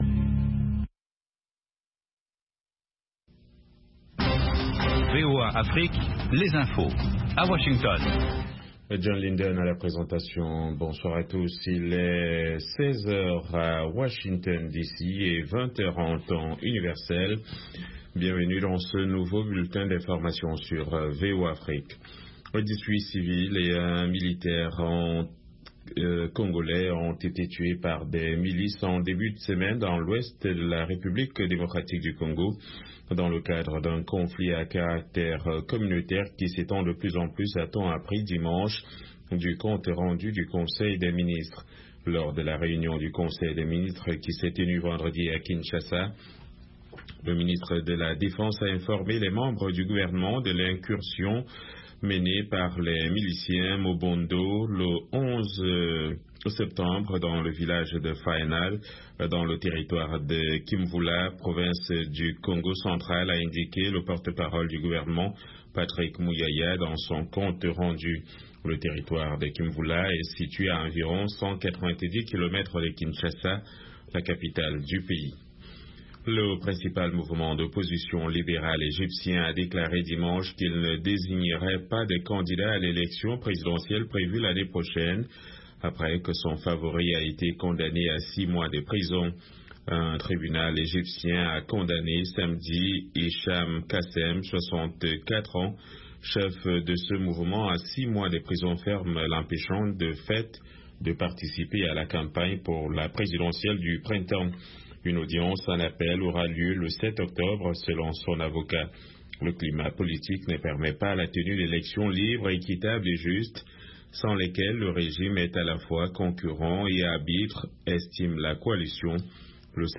Blues and Jazz Program Contactez nous sur facebook